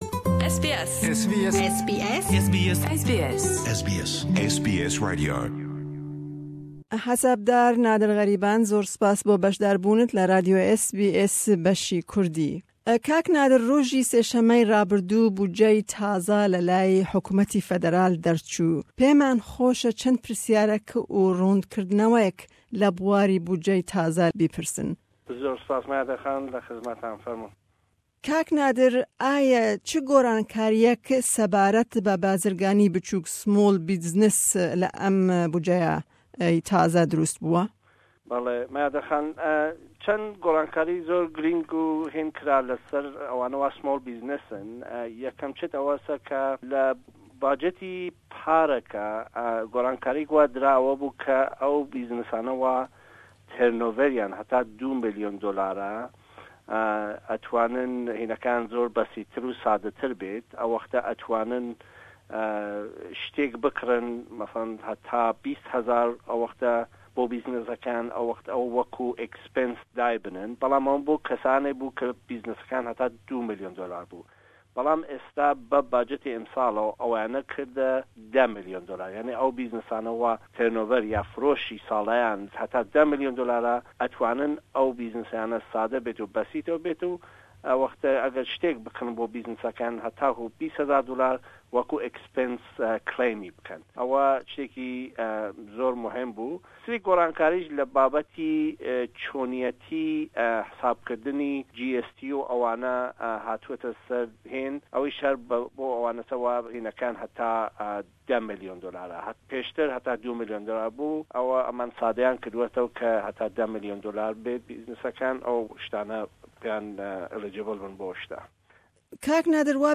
Me hevpeyvînek